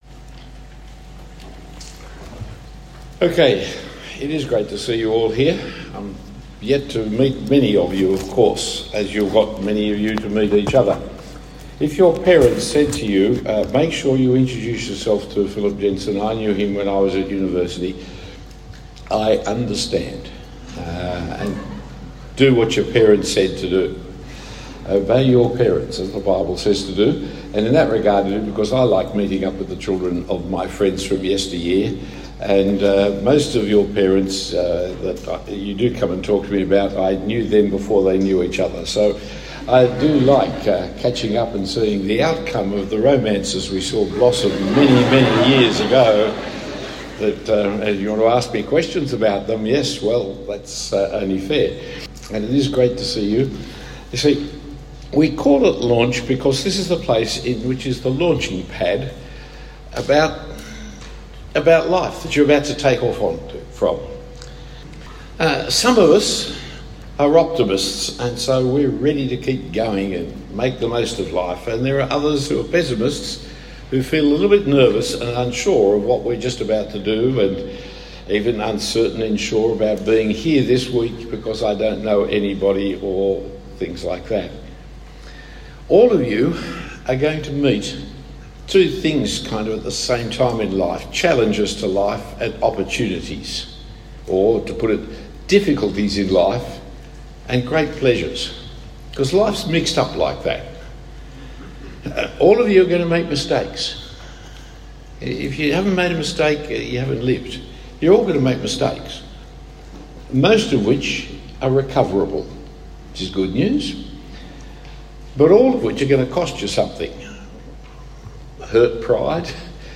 Talk 1 of 6 given at Launch 2025, a camp for school leavers keen to live for Jesus as they commence university life.